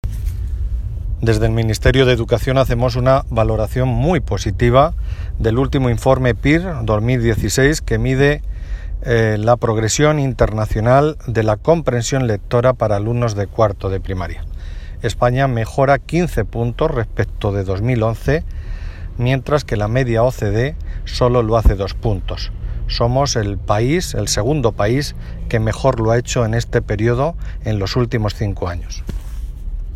Valoración del secretario de Estado de Educación, Formación Profesional y Universidades, Marcial Marín Audio